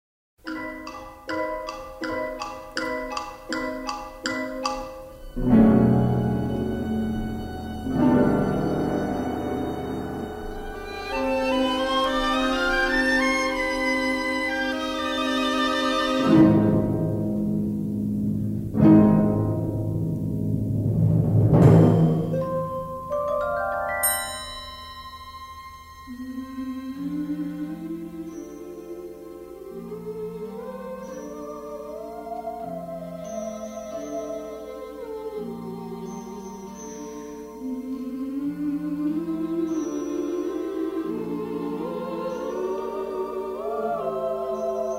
released in stereo in 1959